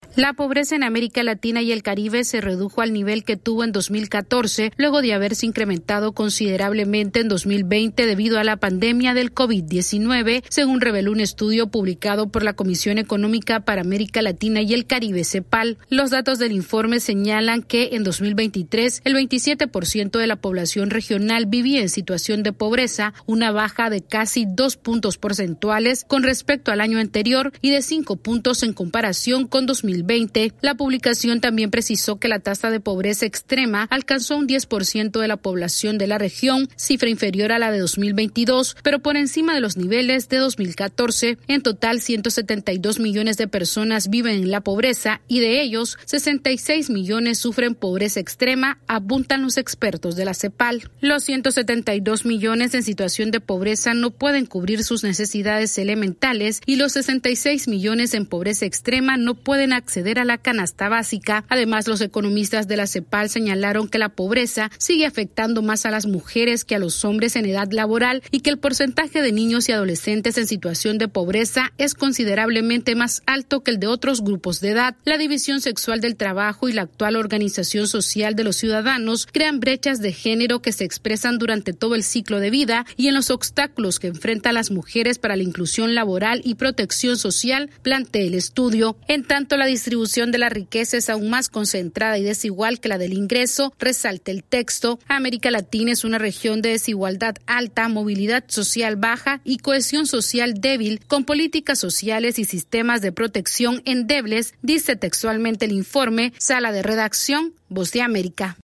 AudioNoticias
Un informe de la CEPAL reveló que la pobreza en América Latina disminuyó luego de la pandemia del COVID -19, sin embargo la región continúa enfrentando gran desigualdad. Esta es una actualización de nuestra Sala de Redacción.